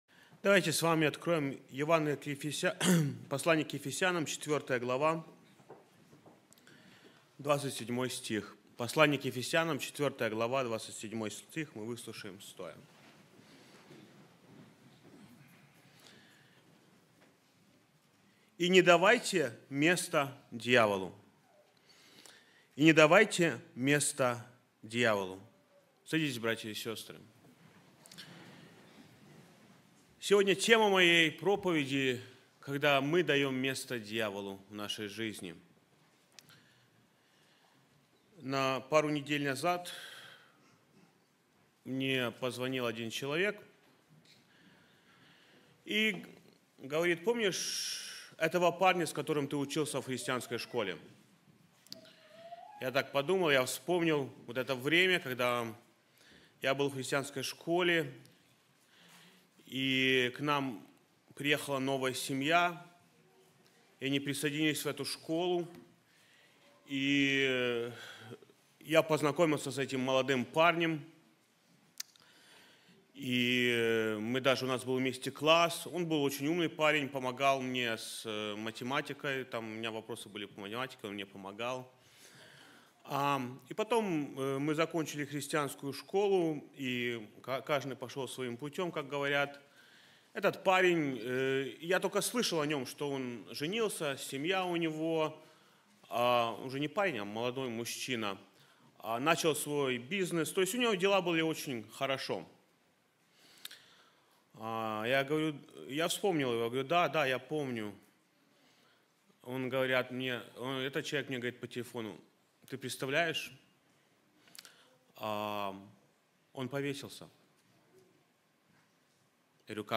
sermon-do-not-give-place-to-devil.mp3